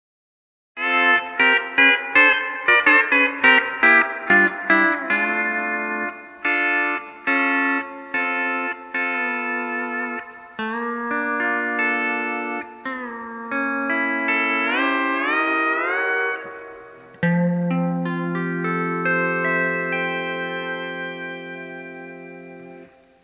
As shown on the audio clip below you can play a whole melody chord sequence without leaving strings 2,3 and 5 (the 1st 8 triads you hear in the clip below).
This clip starts out in F on strings 2,3,5 at fret 5 with no pedals.
End with strum in F with just the E to F change activated for a nice major ninth sound.
This was actually played on my 12 string Excel with the level flipped over to C6.